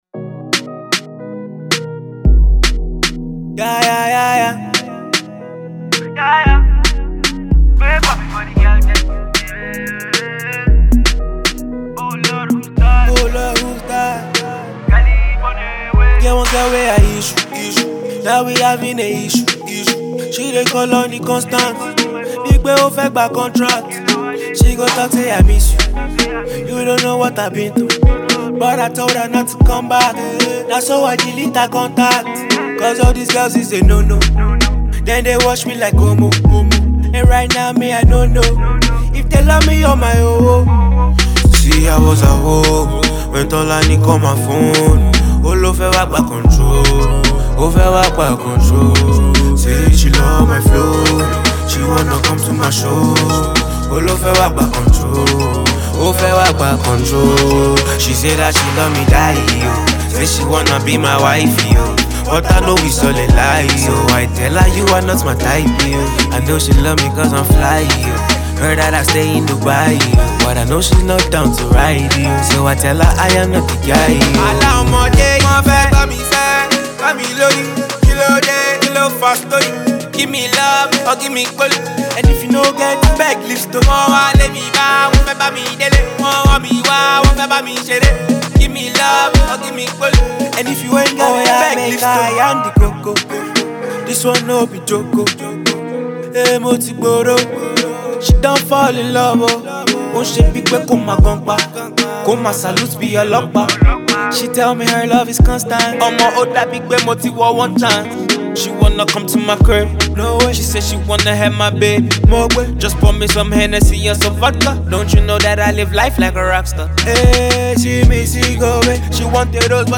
Afro-pop/Afro-fusion